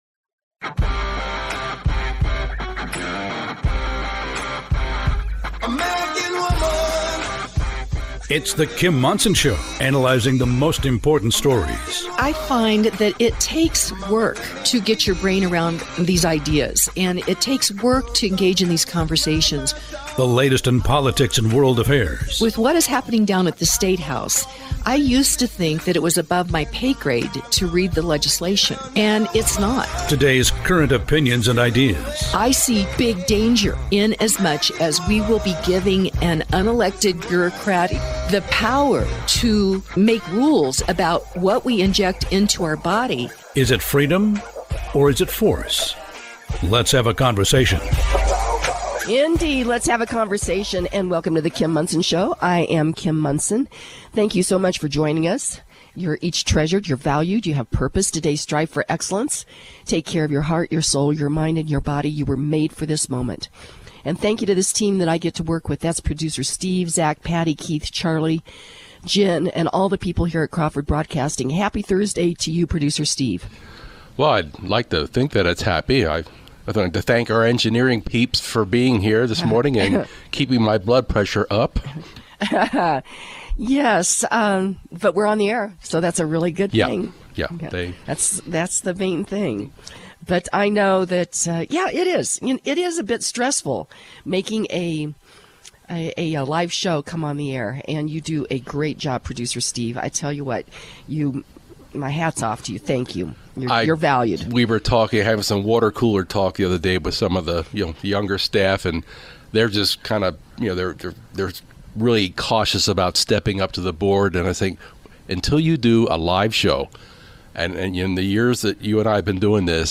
Candidate Interviews